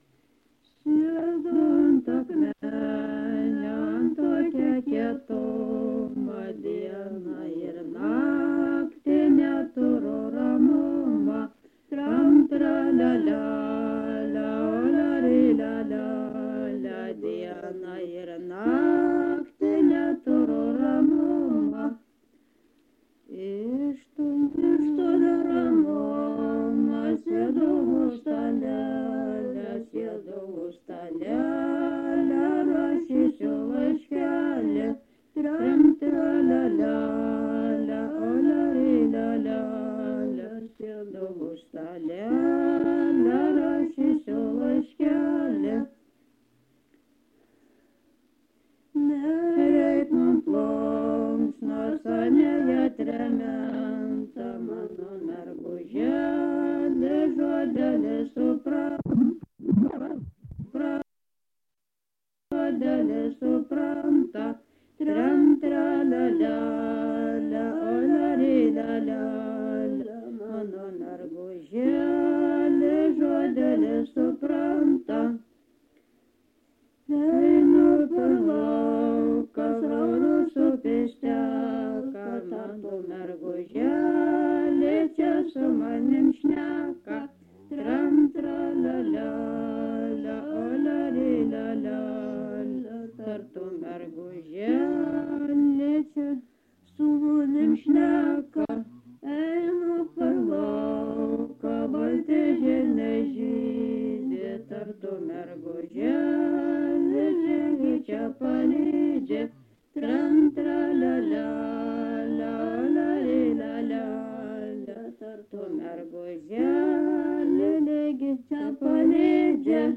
vokalinis
Garsas vietom iškreiptas (dėl juostos perrašinėjimo greičio?)